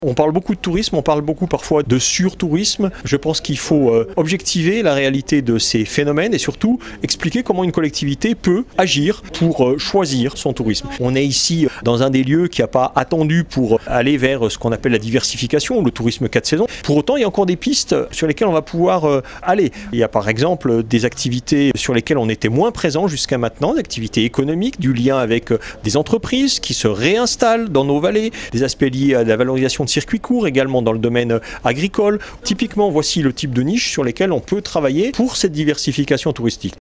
En matière de diversification touristique par exemple, des pistes restent encore à explorer comme l’explique Eric Fournier le maire de Chamonix :